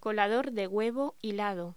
Locución: Colador de huevo hilado
voz